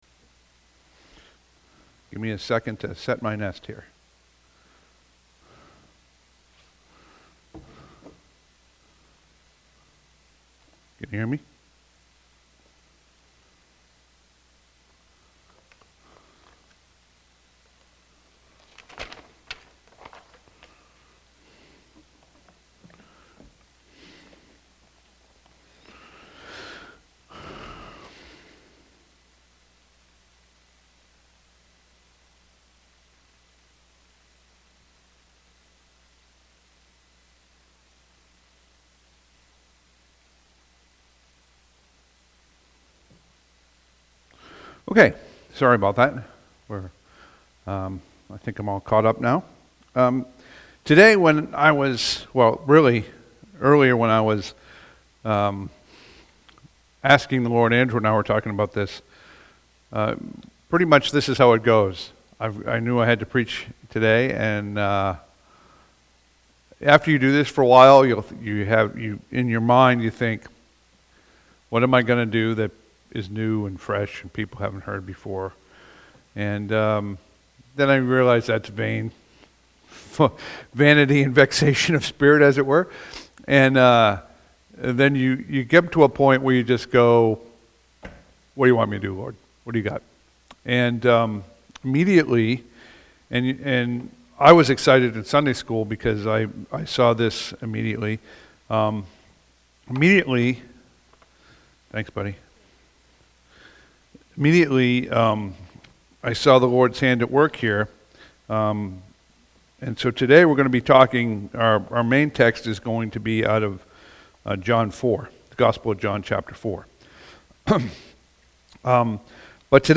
Passage: Matthew 28:19-20, John 4:1-42 Service Type: Sunday AM